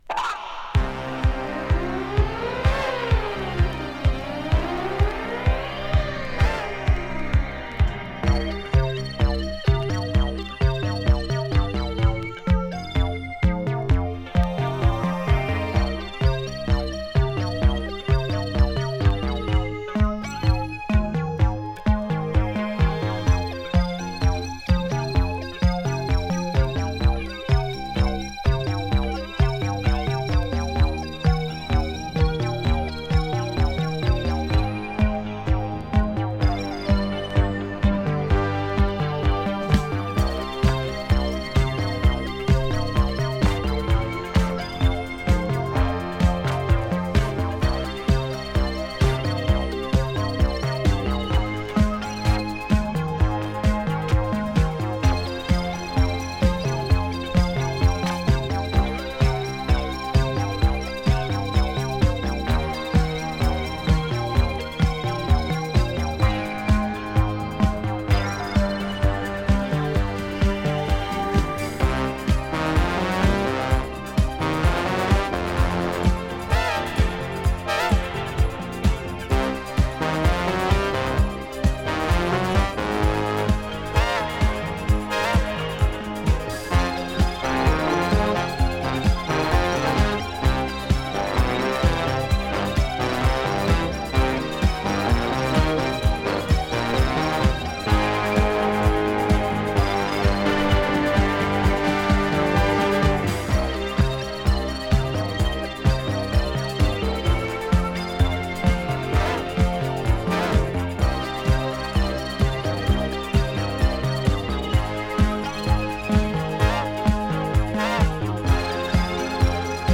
フランス産スペース・ディスコ！
コズミックなシンセサウンドをフューチャーしたスペース・ディスコナンバー